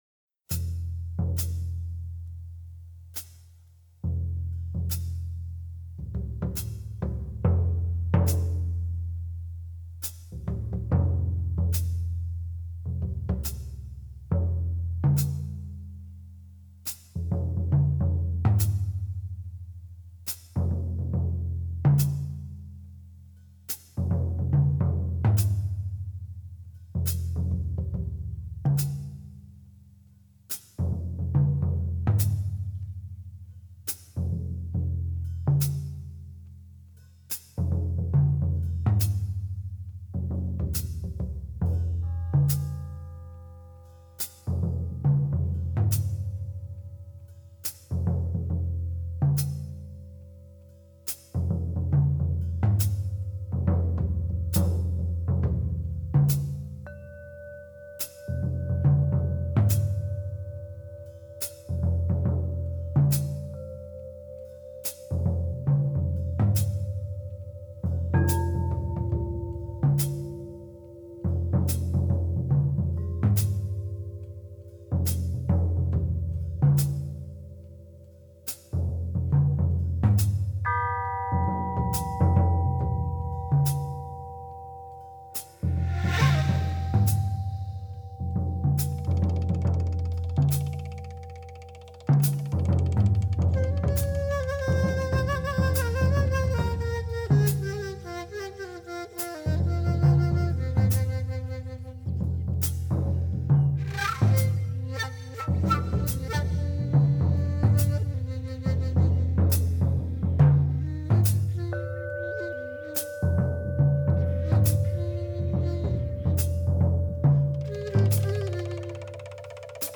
Australasian flute, mouthbows and vocals